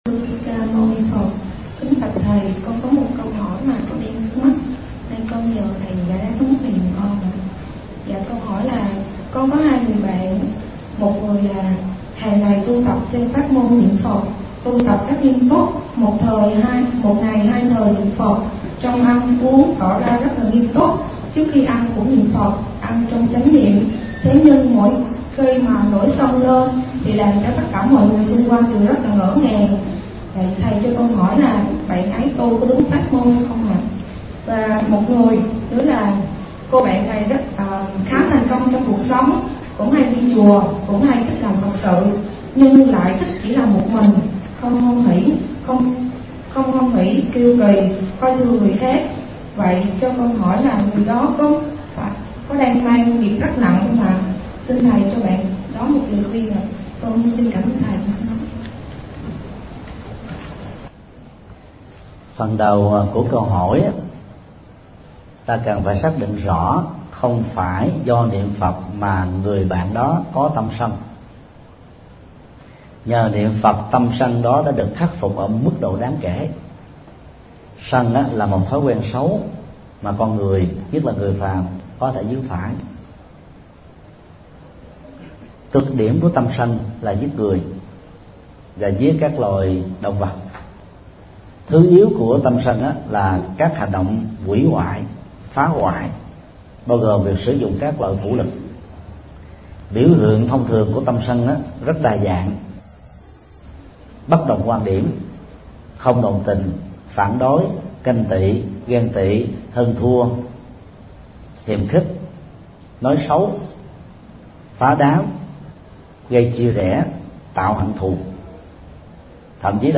Vấn đáp: Giải thích về tâm sân, niệm Phật, đồng tu – Thầy Thích Nhật Từ